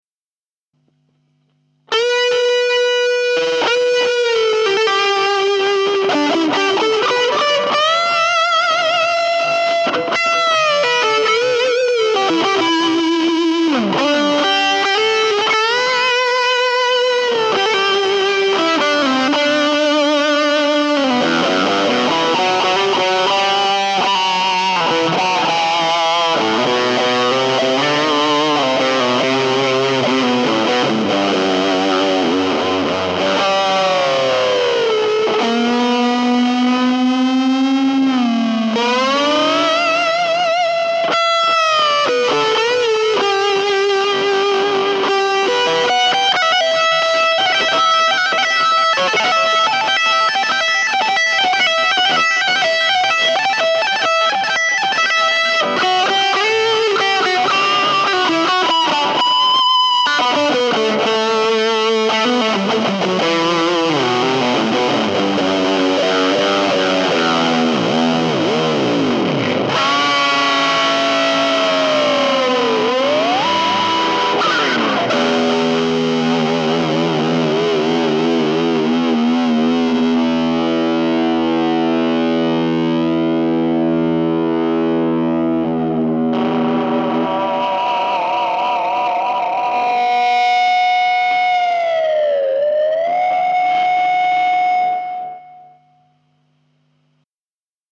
Mooer CruncherПедаль хай-гейн дисторшна.
Mooer RepeaterЦифровой дилей.
2. Cruncher + Repeater 2,3 Мб